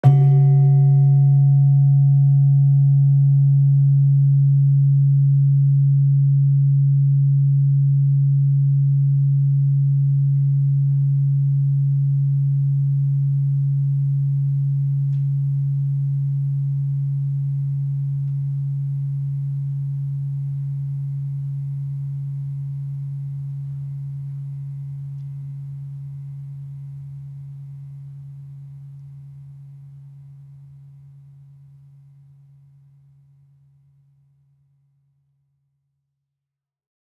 Gender-4-C#2-f.wav